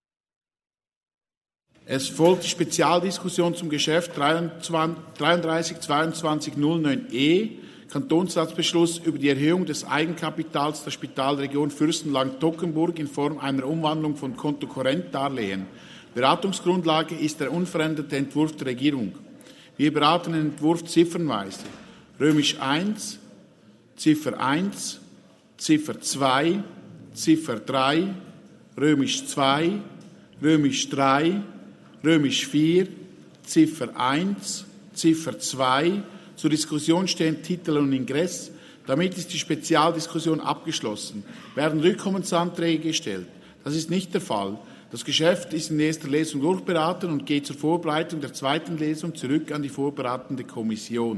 Session des Kantonsrates vom 28. bis 30. November 2022